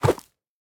Minecraft Version Minecraft Version snapshot Latest Release | Latest Snapshot snapshot / assets / minecraft / sounds / mob / goat / jump1.ogg Compare With Compare With Latest Release | Latest Snapshot
jump1.ogg